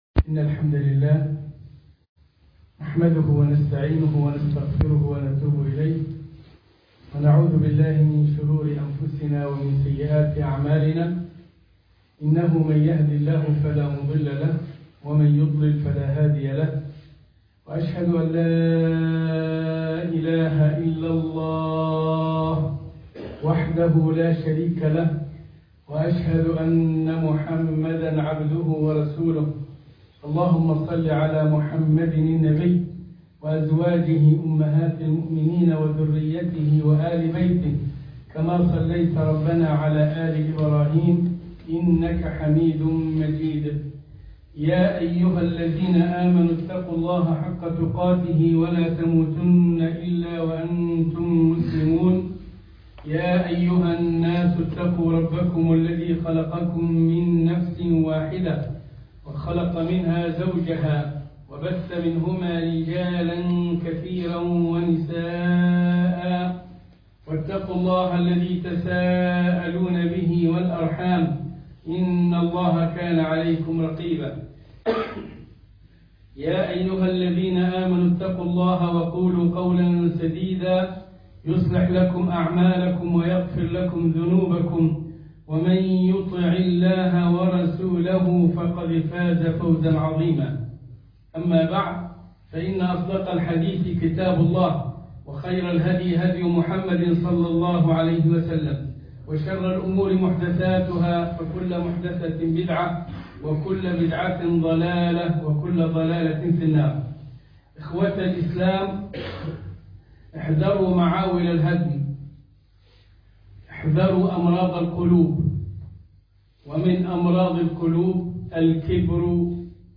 ( احذروا معاول الهدم الكبر )خطبة الجمعة